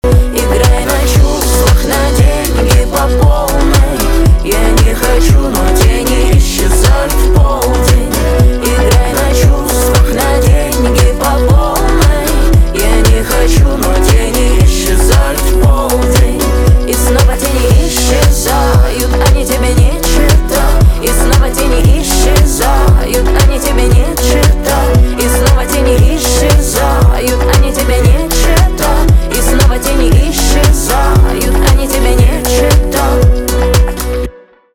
русский рэп , битовые , басы , гитара
грустные